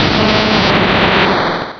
pokeemerald / sound / direct_sound_samples / cries / steelix.aif